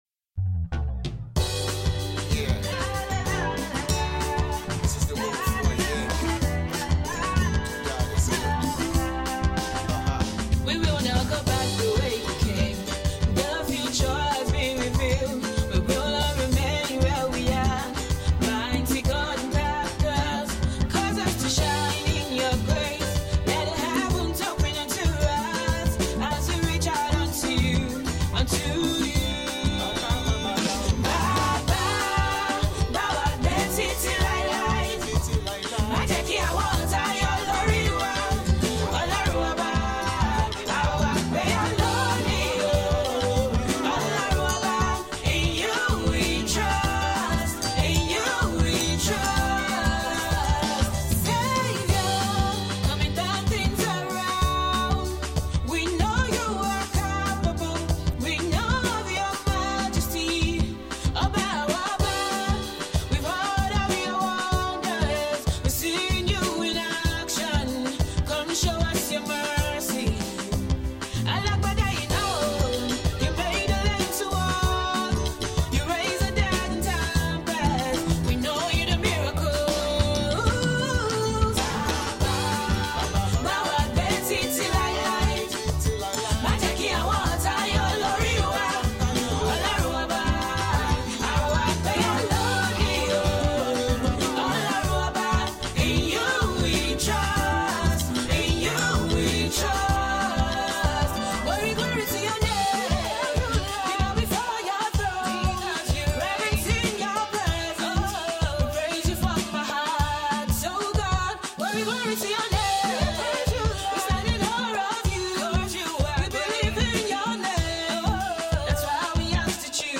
gospel single